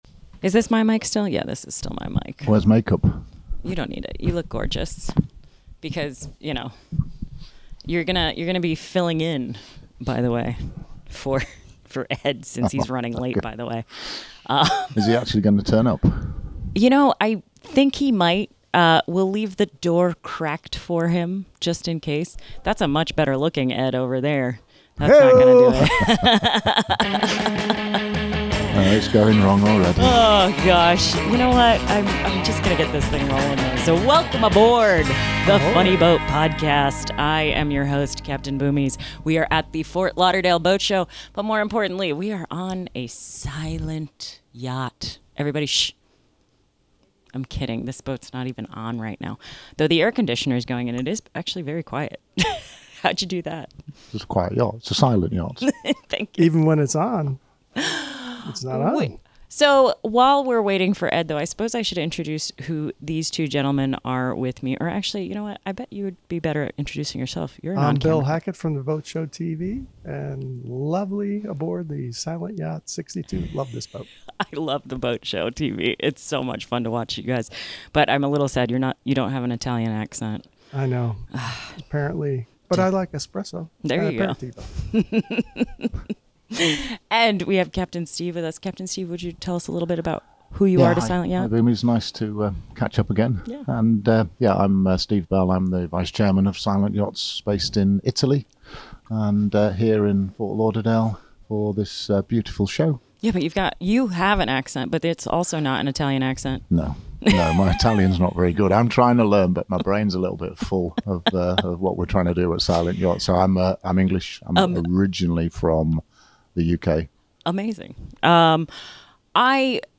Ep 42 - Silent Yachts Atlantic Crossing at Ft Lauderdale Boat Show